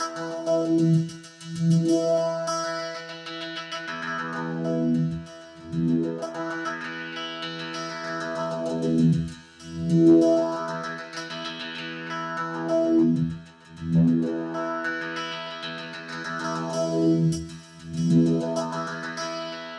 ElectricBerimbau1_97_E.wav